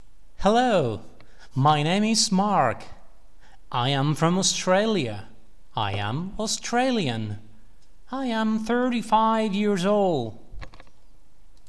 y escucharás a una persona. Deberás rellenar la ficha con sus datos personales.